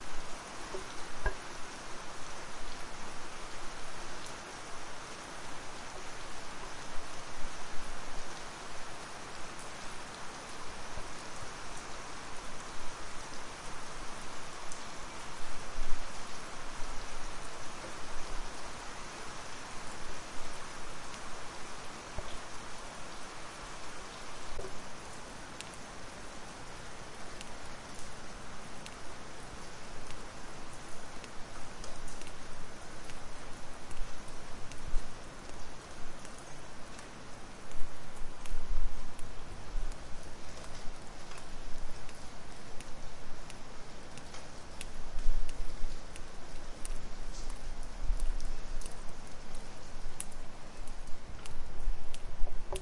雷雨 " 雷雨长版
描述：三段录音粘贴在一起，是在1997年6月德国科隆一场大雨的雷雨中两小时内录制的。DatRecorder.
标签： 场记录 性质 雨水 小麦幼她
声道立体声